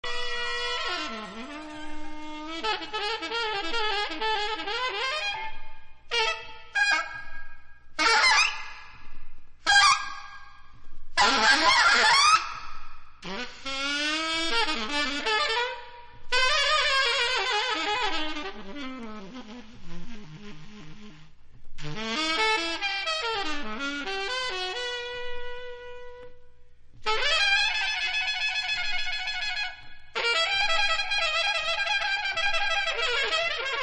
TOP > Future Jazz / Broken beats > VARIOUS